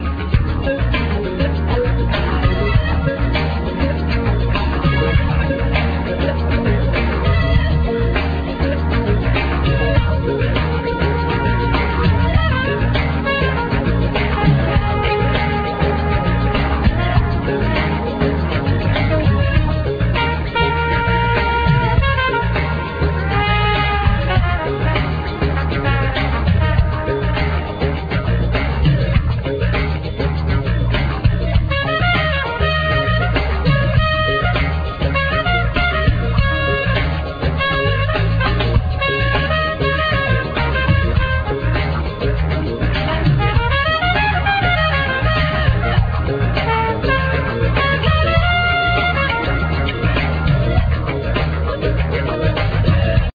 Trumpet
Turntables
Drums
Bass
Fender piano
Synthsizer, Hammond organ
African percussions
Guitar
Vocal, Violin